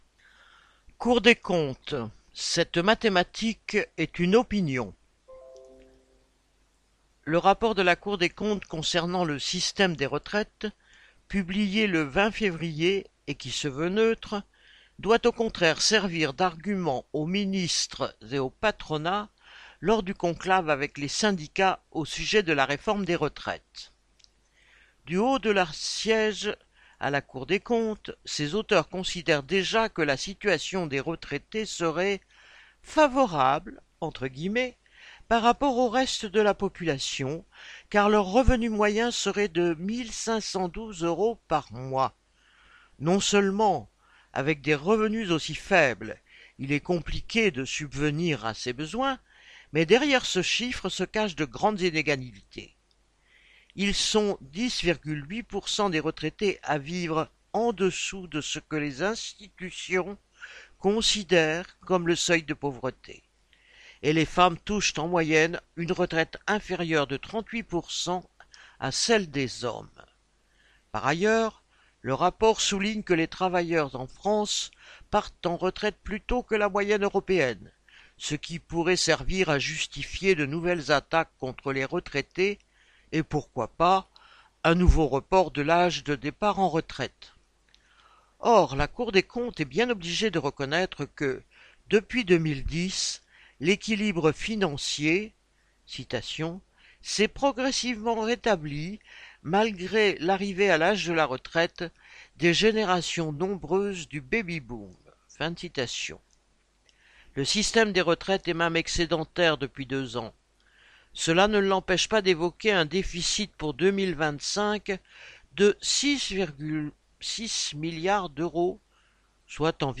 Version audio des articles du journal Lutte ouvrière de la semaine